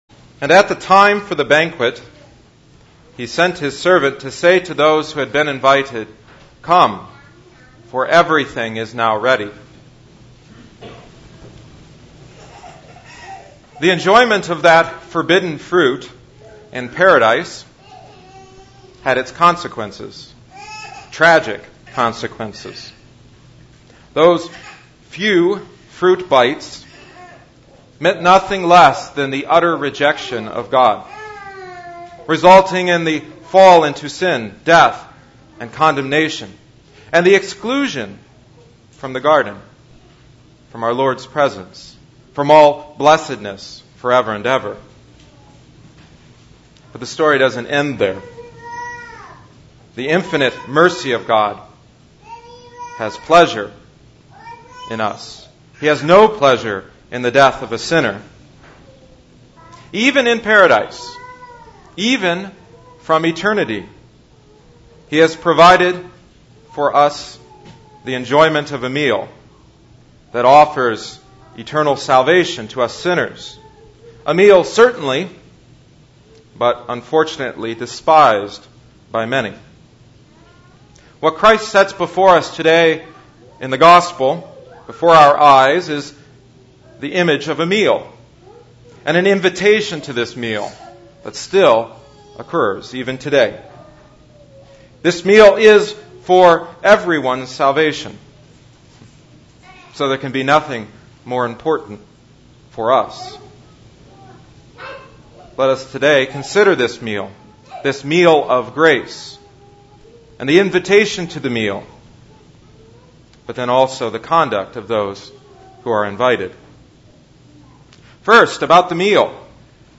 (Divine Service 2011-07-03 Audio only)